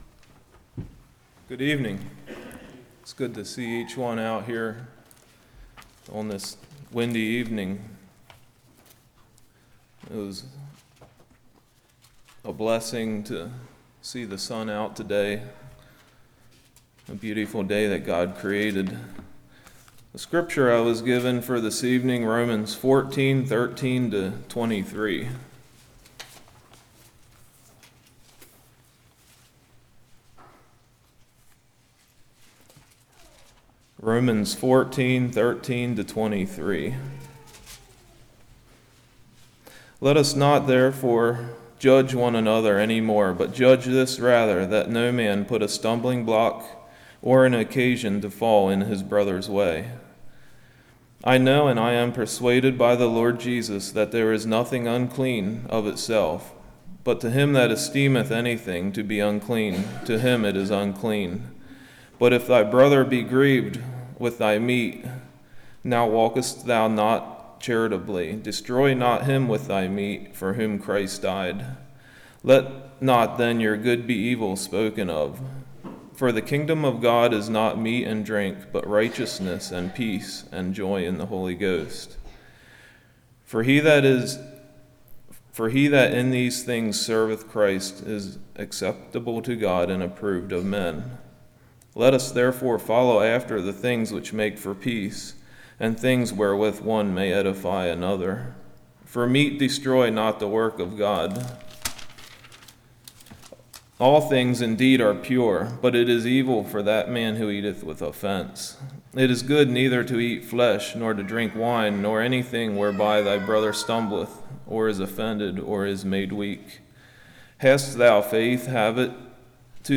Service Type: Evening